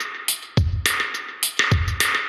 Index of /musicradar/dub-designer-samples/105bpm/Beats